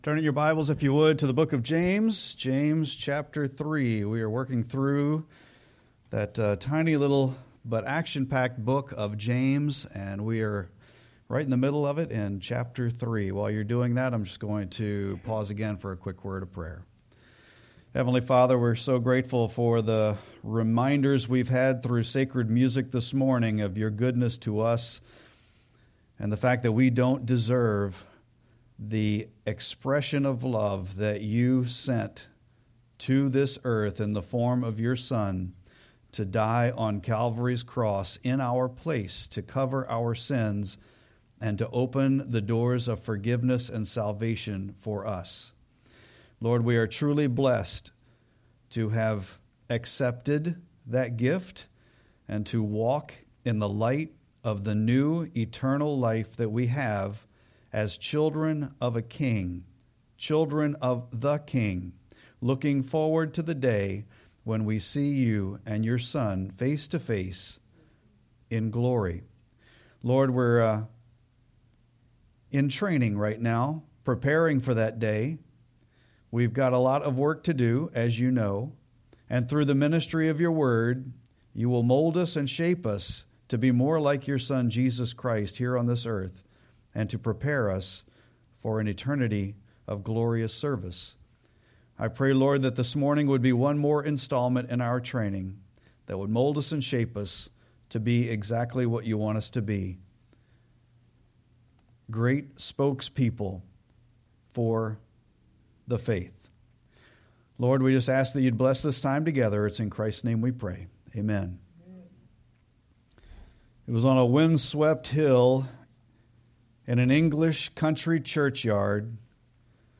Sermon-8-3-25.mp3